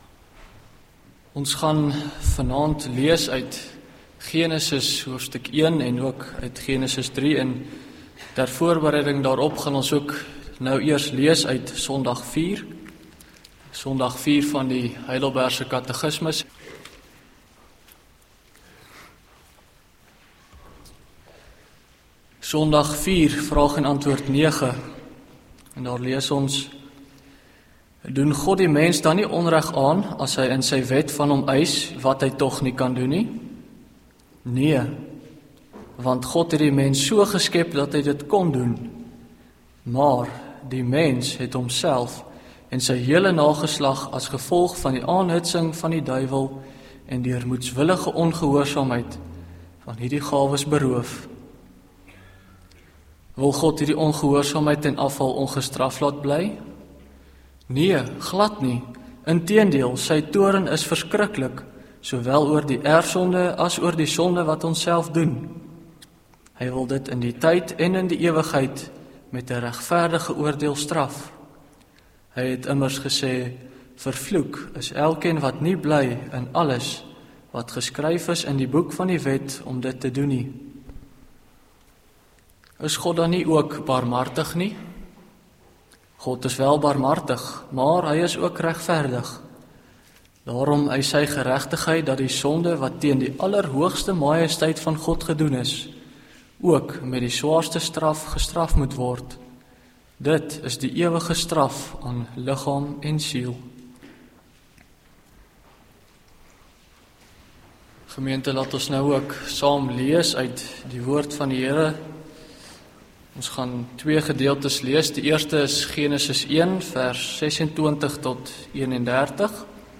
Link Preek Inhoud